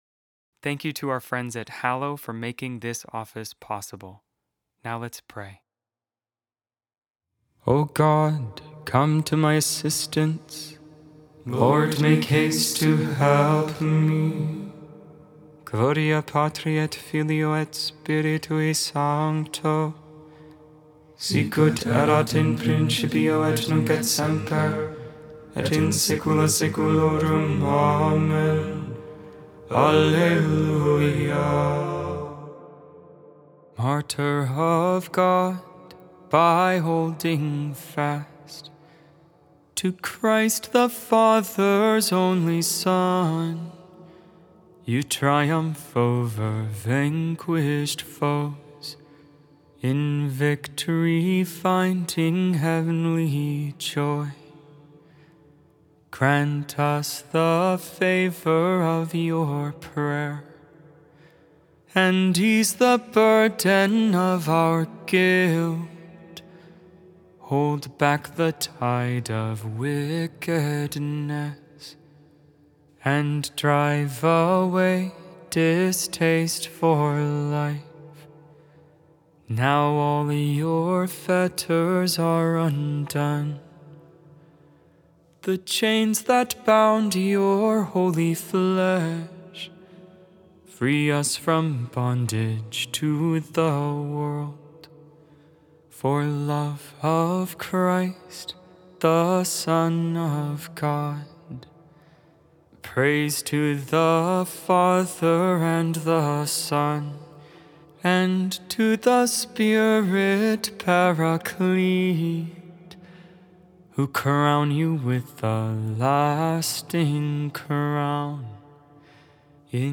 100% human vocals, 100% real prayer.